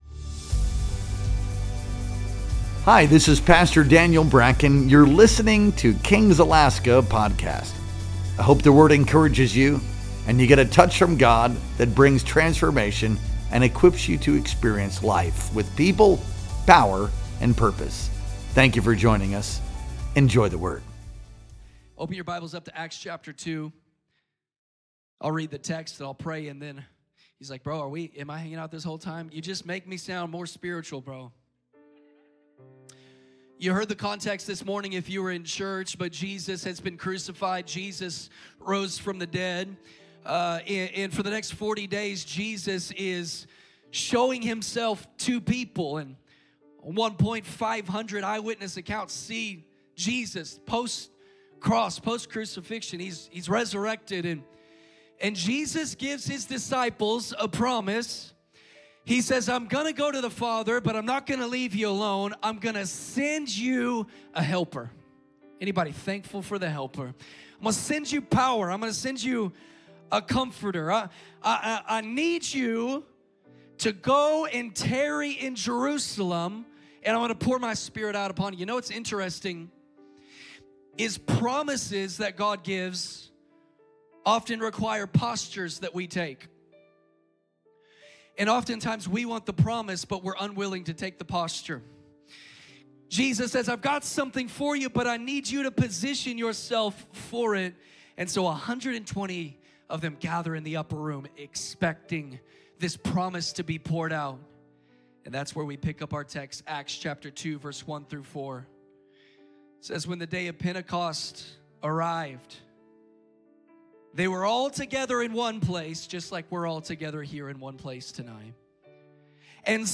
Our Sunday Night Worship Experience streamed live on June 8th, 2025.